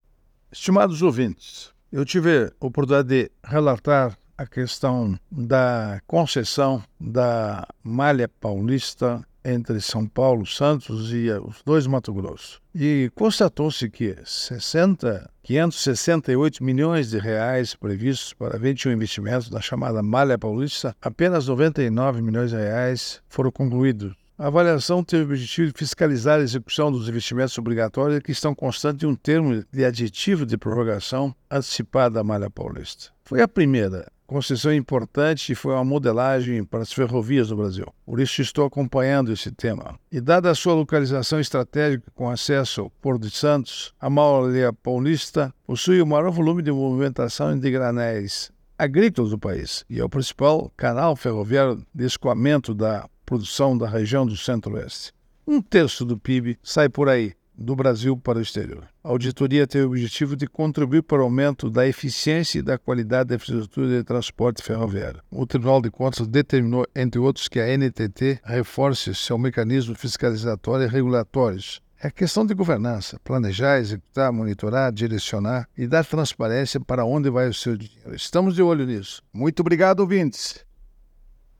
É o assunto do comentário do ministro do Tribunal de Contas da União, Augusto Nardes, desta terça-feira (18/06/24), especialmente para OgazeteirO.